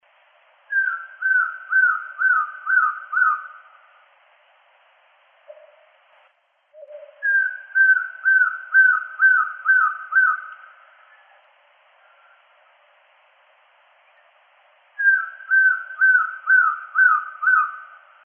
Atlantic Black-throated Trogon (Trogon chrysochloros)
Life Stage: Adult
Province / Department: Misiones
Location or protected area: Reserva Privada y Ecolodge Surucuá
Condition: Wild
Certainty: Recorded vocal